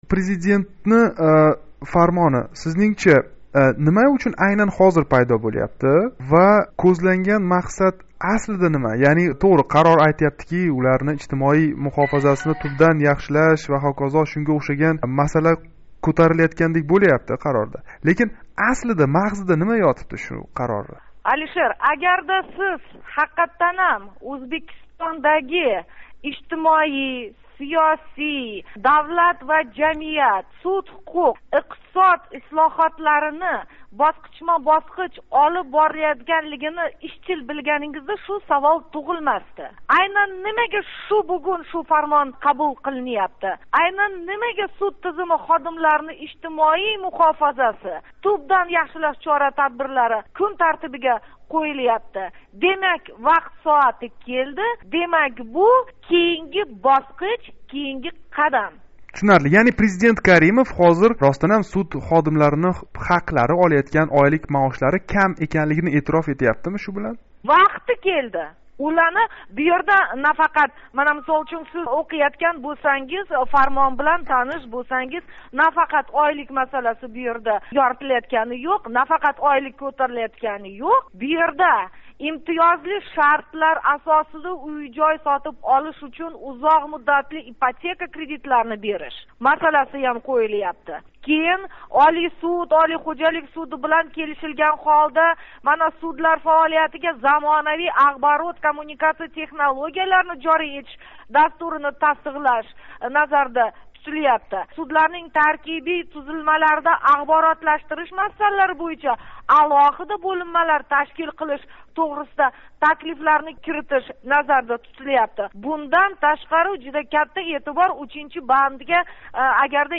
Киритиш (Embed) Улашиш Светлана Ортиқова билан суҳбат билан Озодлик радиоси Киритиш (Embed) Улашиш Коддан алмашув буферингизга нусха кўчирилди.